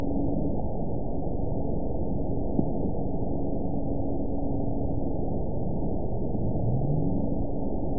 event 922869 date 04/29/25 time 15:23:30 GMT (1 month, 2 weeks ago) score 9.43 location TSS-AB02 detected by nrw target species NRW annotations +NRW Spectrogram: Frequency (kHz) vs. Time (s) audio not available .wav